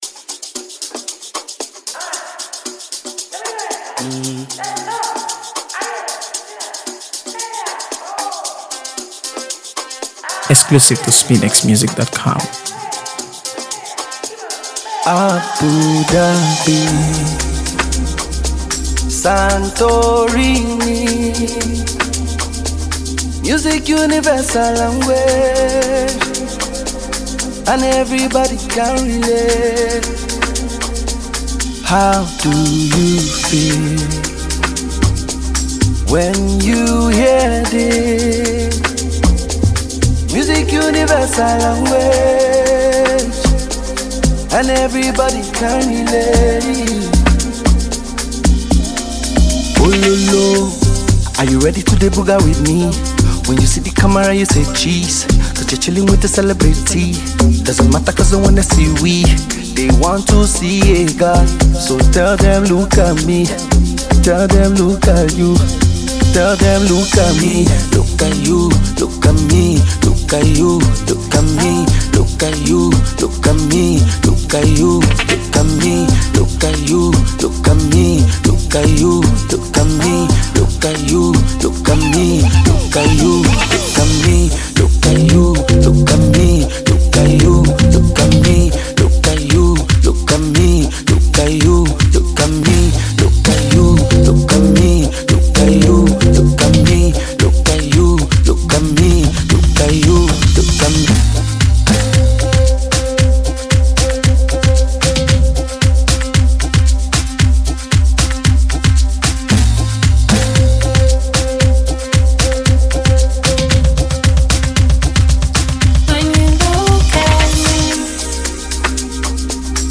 AfroBeats | AfroBeats songs
Known for his smooth vocals and genre-blending style
rich harmonies and a distinct cultural blend